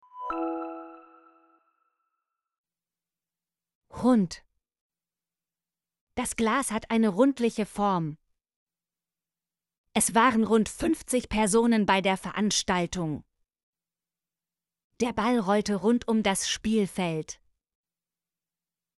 rund - Example Sentences & Pronunciation, German Frequency List